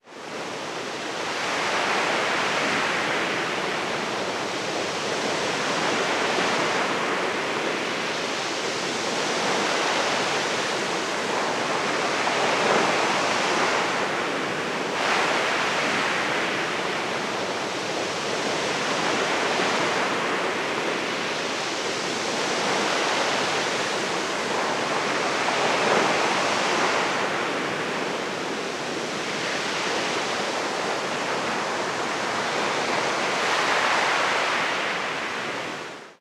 Ambiente de mar con olas 2